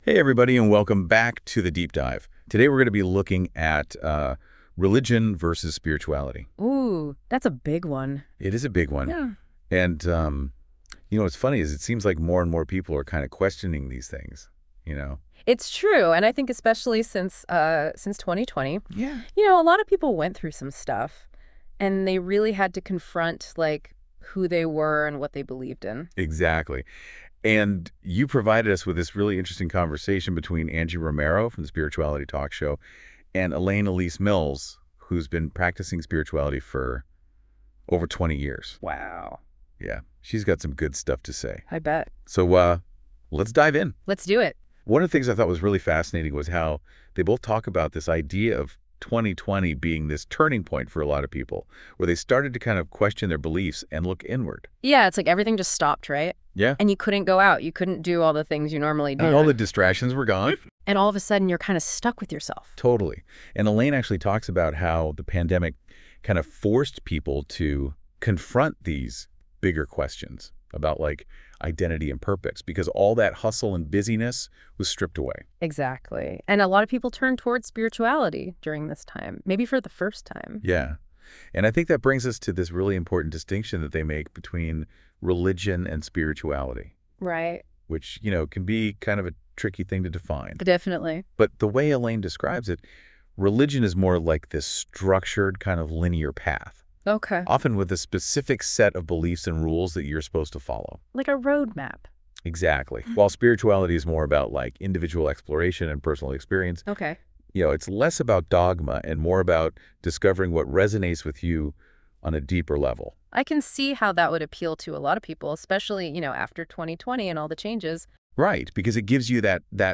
Check out this Deep Dive interview regarding this issue!